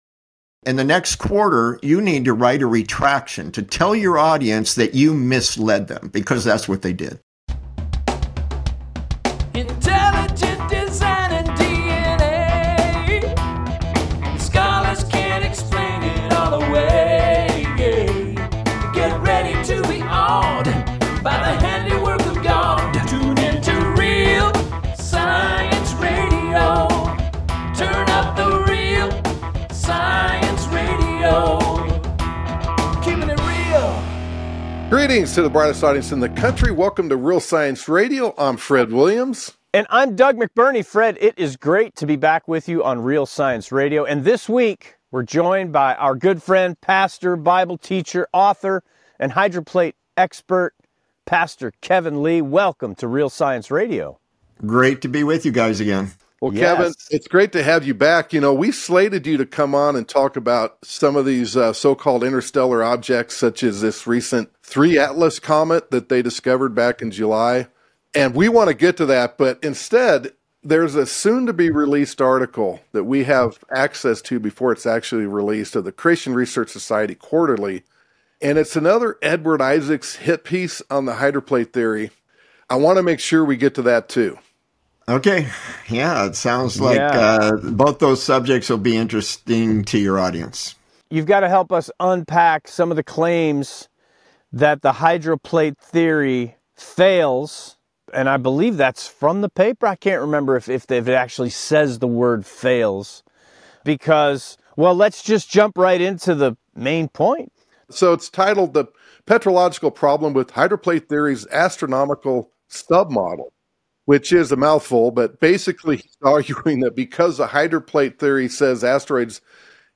Friday's Broadcast